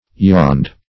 yawned - definition of yawned - synonyms, pronunciation, spelling from Free Dictionary
Yawn \Yawn\ (y[add]n), v. i. [imp. & p. p. Yawned; p. pr. &